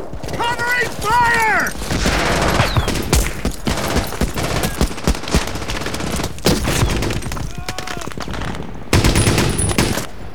I found one with lots of shooting and screaming.
There's something at the end where you can hear a faint screaming and before that, maybe a spitting?
As you can see, it's a blow on the mirror, not a spit.
There’s a close-to-camera-ricochet sound and I wanted it to be a shot to the ground close to him, spilling up dirt and stones and have that hit his face (maybe he
In "Ryan" it's just a moan/scream off-screen, but I wanted to show the guy being hit.
But then again, I really liked the "Covering fire!!" part, so I edited the whole thing around and ended up with a 10sec. piece (damn it, that always happens, I never settle on something short...).
ricochetdeath_customedit.wav